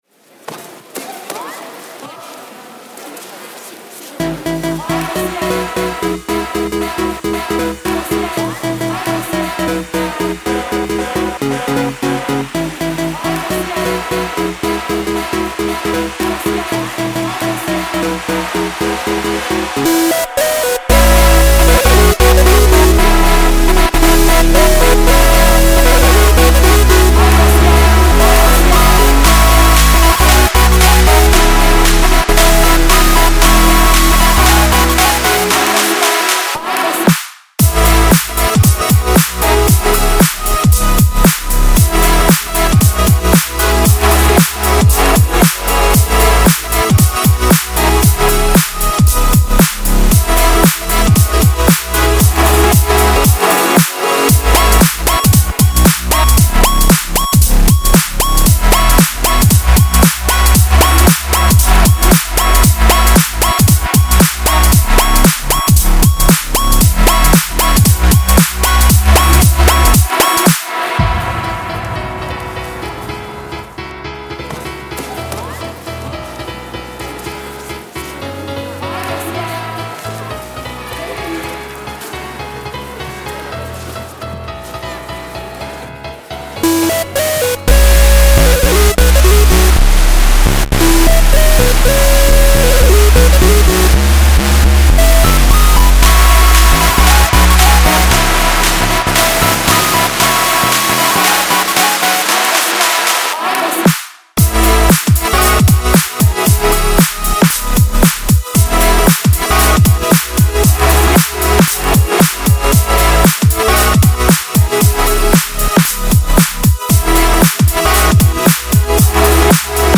I've decided to make one of the more difficult genres "Glitch Hop", which works exceptionally well when it comes to resampling.
Verona Arena reimagined
——————— This sound is part of the Sonic Heritage project, exploring the sounds of the world’s most famous sights.